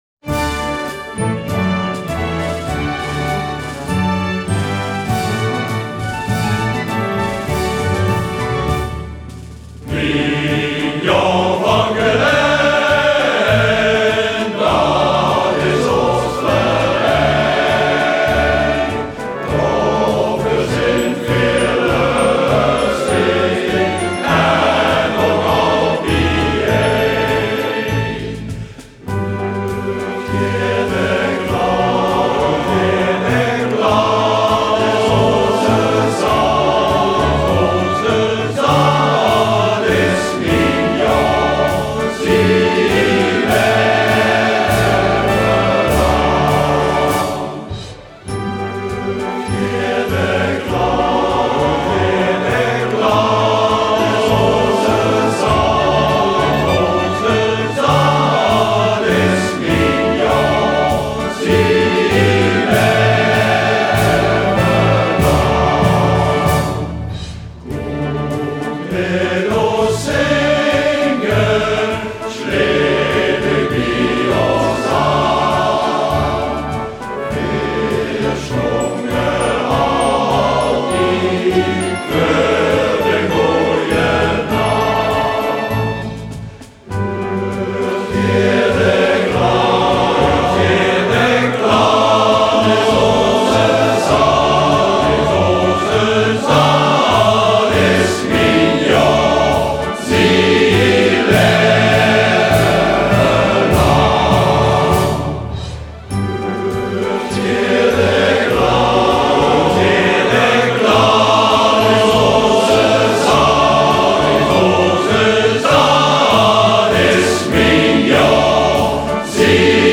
Geleens Mannenkoor Mignon
Mignon+van+Gelaen+opname+studio.mp3